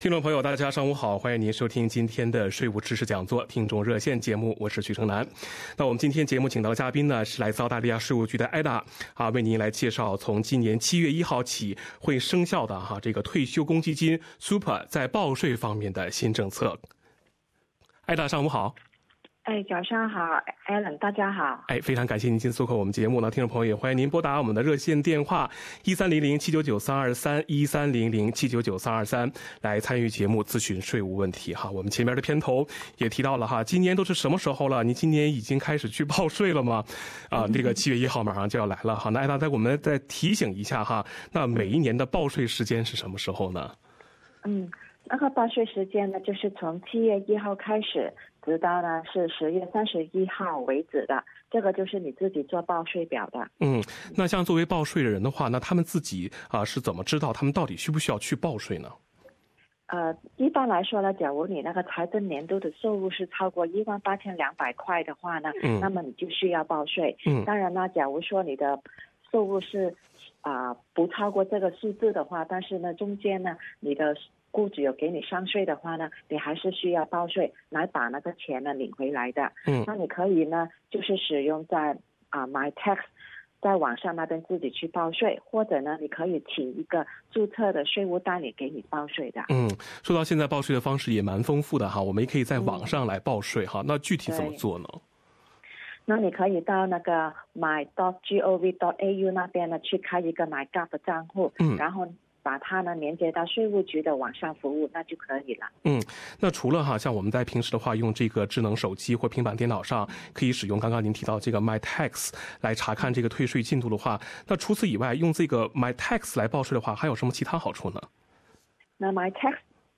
《税务知识讲座-听众热线》逢每月第一个周一上午8点30分至9点播出。